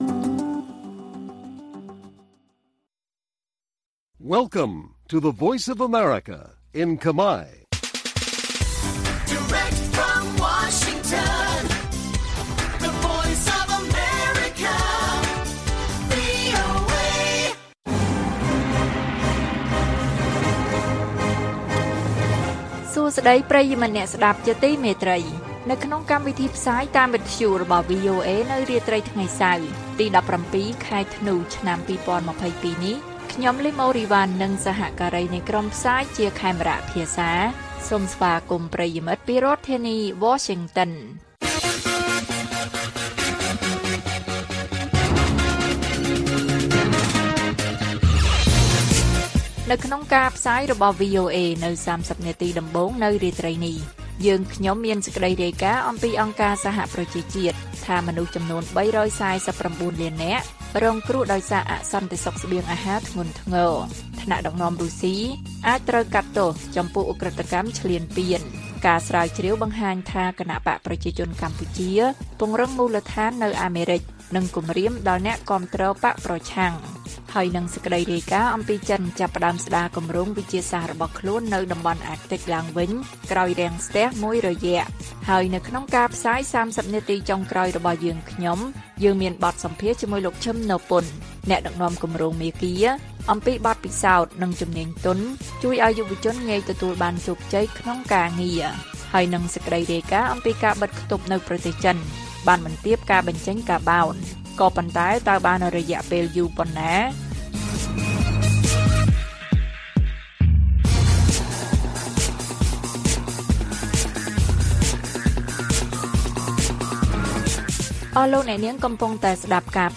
ព័ត៌មានពេលរាត្រី ១៧ ធ្នូ៖ អង្គការសហប្រជាជាតិថា មនុស្ស៣៤៩លាននាក់រងគ្រោះដោយសារអសន្តិសុខស្បៀងអាហារធ្ងន់ធ្ងរ